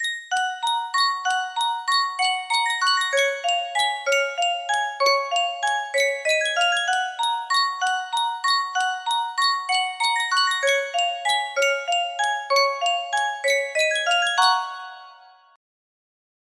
Yunsheng Music Box - Cuckoo Waltz 5625 music box melody
Full range 60